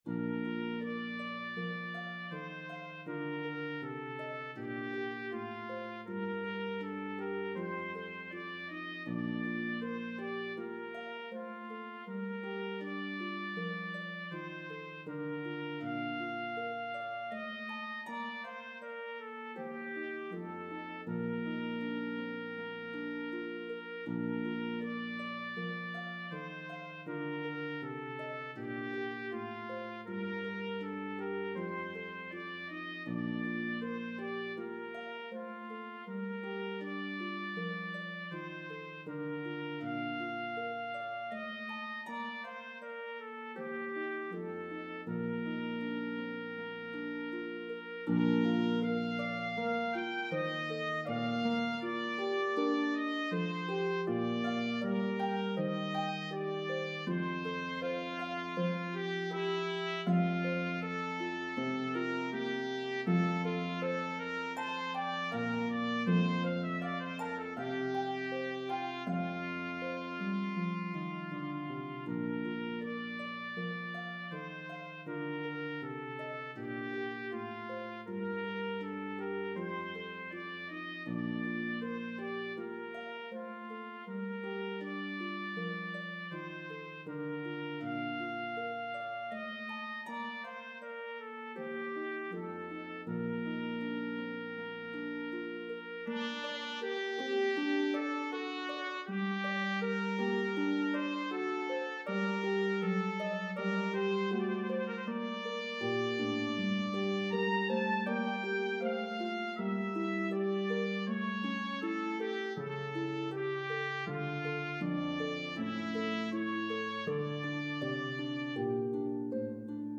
This well known Baroque piece